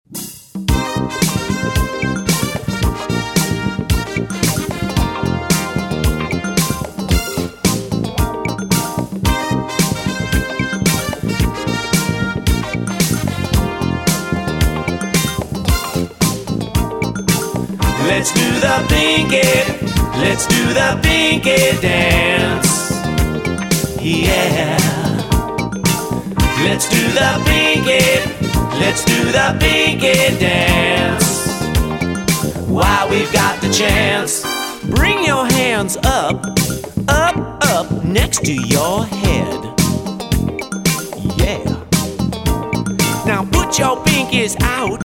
movement songs